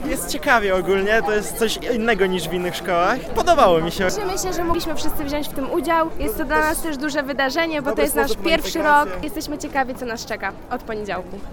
Za nami Spacerek na uniwerek, czyli uroczysty przemarsz rektorów i studentów wszystkich szczecińskich uczelni.